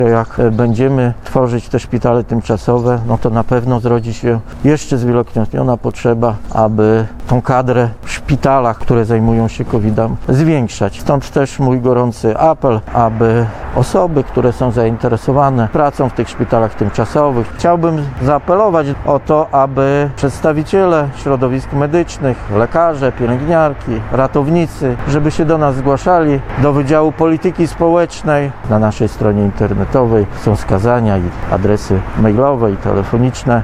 Wojewoda zaapelował do przedstawicieli środowisk medycznych, aby zgłaszali się do pracy w szpitalu tymczasowym.
wojewoda3.mp3